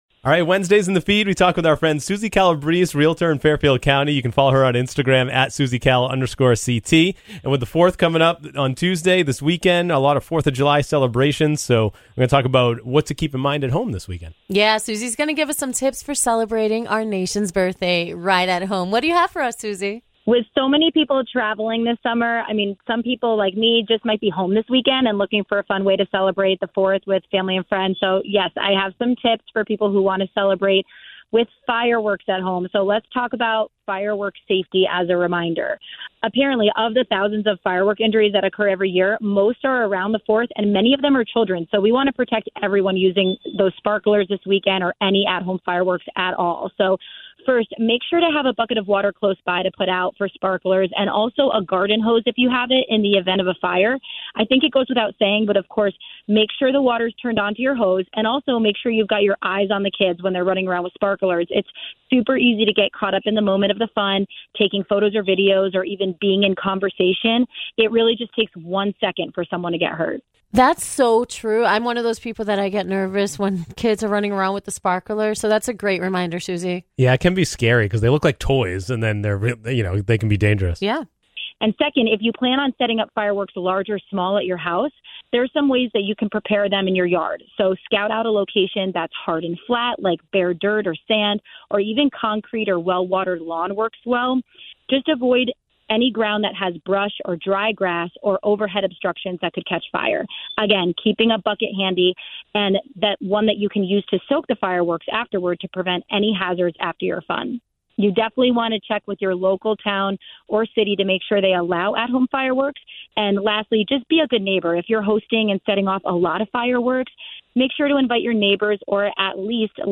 chat with a local expert